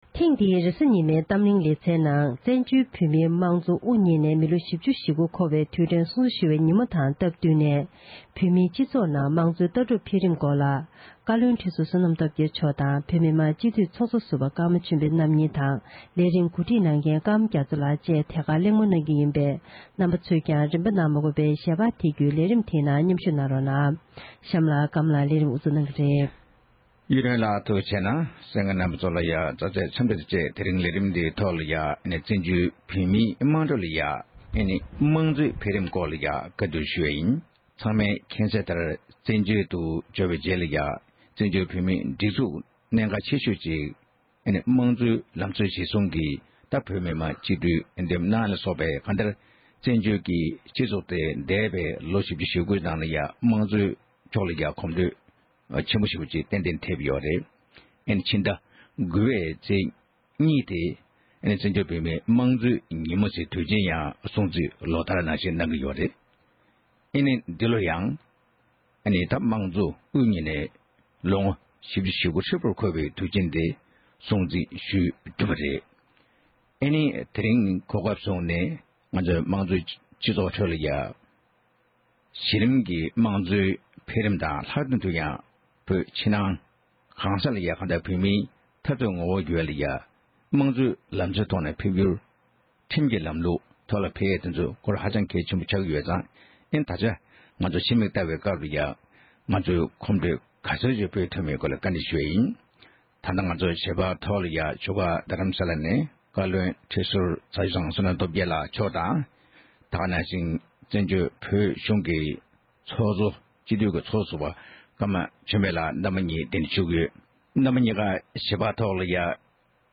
བོད་མིའི་སྤྱི་ཚོགས་ནང་དམངས་ཙོའི་ལྟ་གྲུབ་འཕེལ་རིམ་སྐོར་གྱི་བགྲོ་གླེང༌།